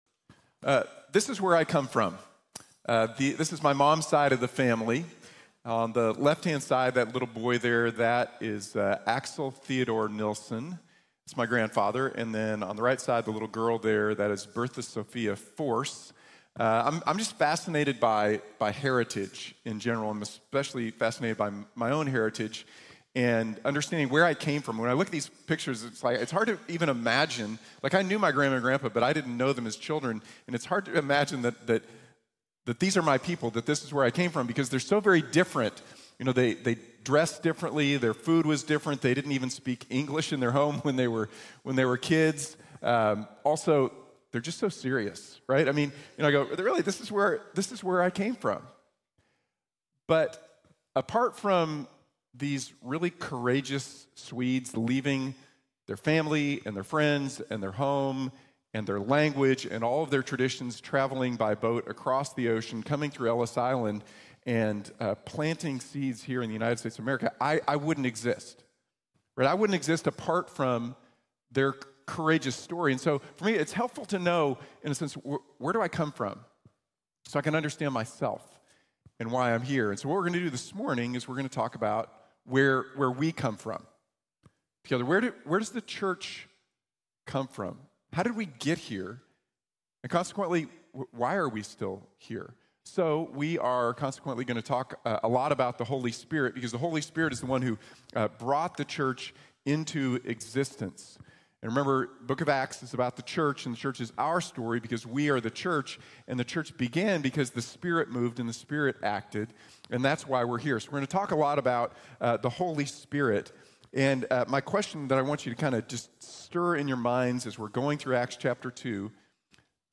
Born of the Spirit | Sermon | Grace Bible Church